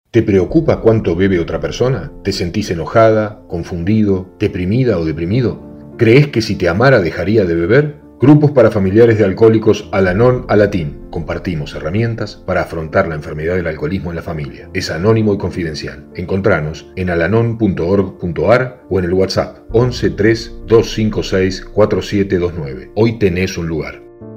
Spots de radio
Spot-alanon-radio-2021.mp3